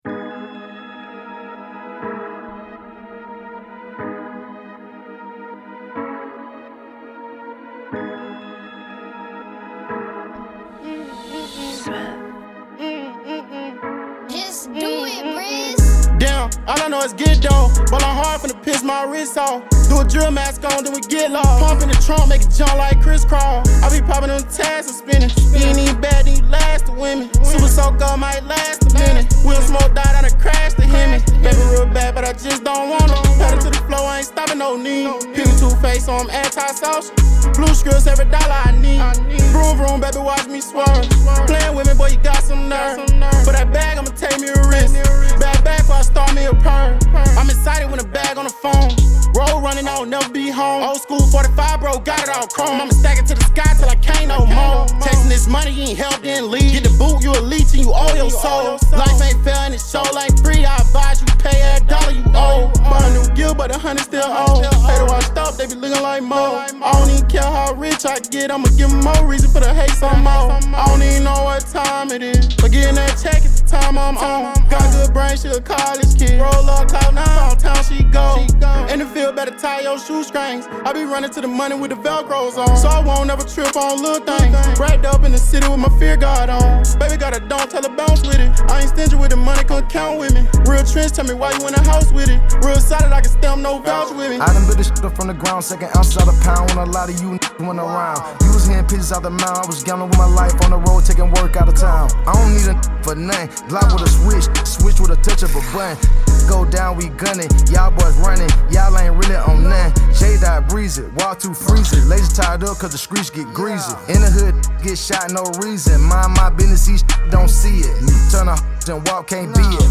Hiphop
the radio version of this song